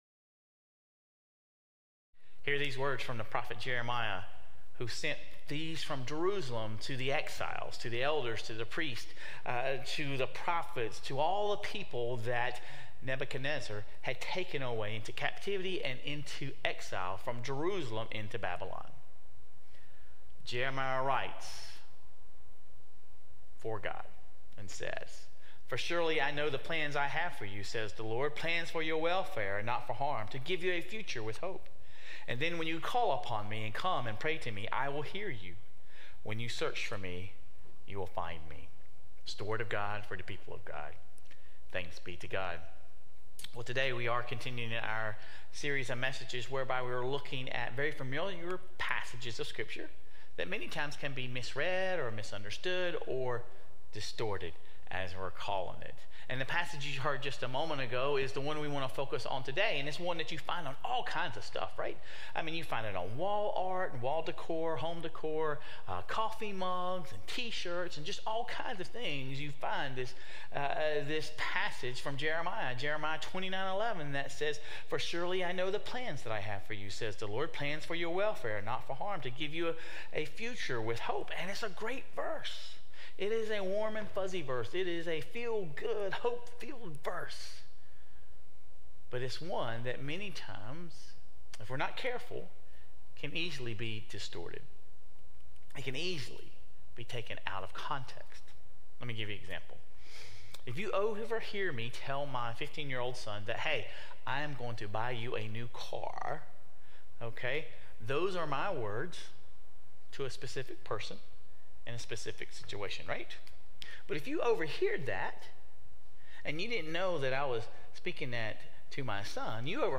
This week, we turn our attention to the concept of hope. Sermon Reflections: False Hope vs. True Hope